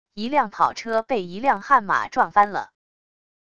一辆跑车被一辆悍马撞翻了wav音频